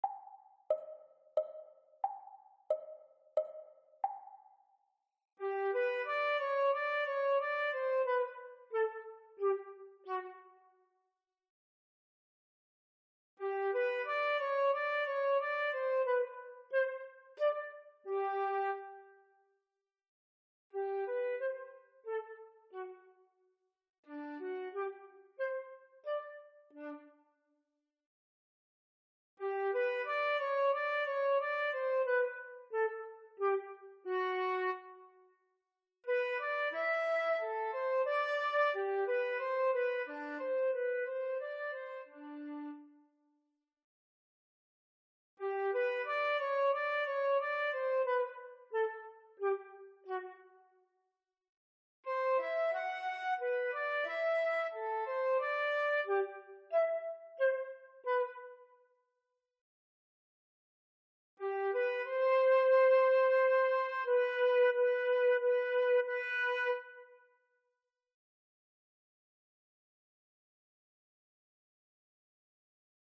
2020 Flute Duet Recordings Practise Speed - MTB Exams
Flute-Grade-3-Haydn-Minuet-Practise-Speed.m4a